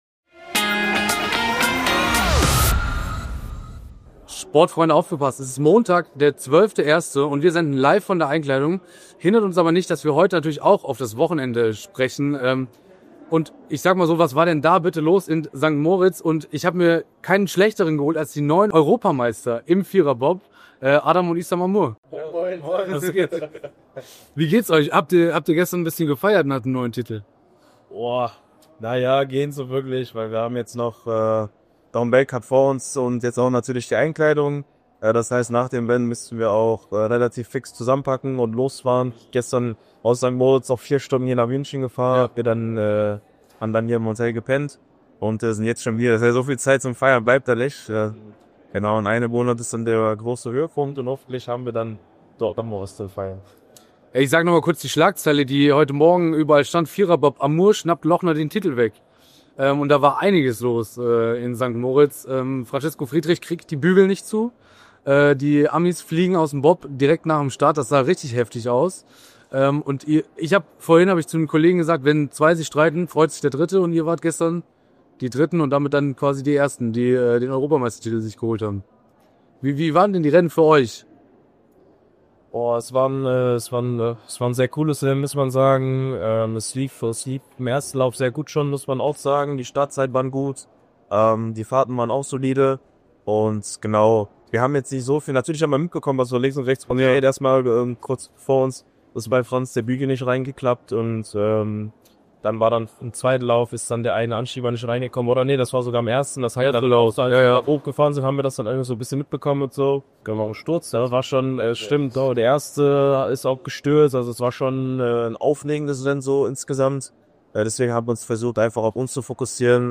Sportfreunde aufgepasst, es ist Montag, der 12.01. und wir senden LIVE von der Einkleidung hindert uns aber nicht, dass wir auch heute natürlich über das Wochenende sprechen müssen!